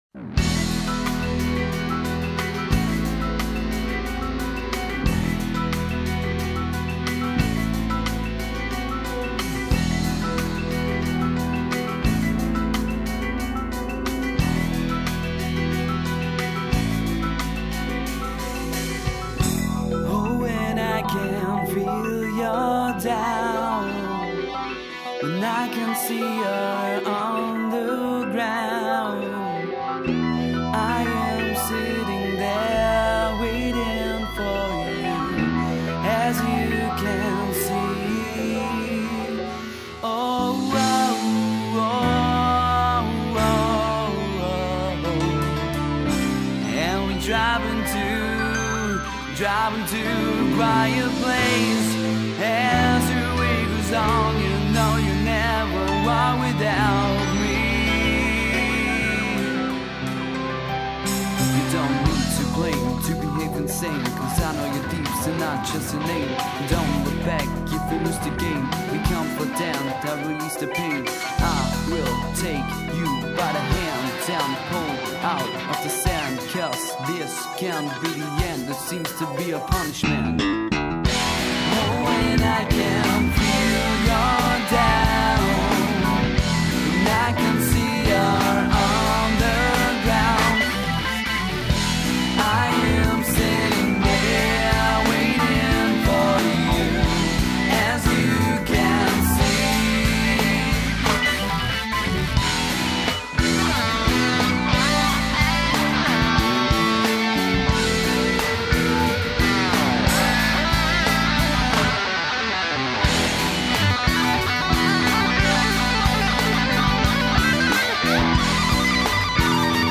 keys, sax
Progressive Crossover
Demo Songs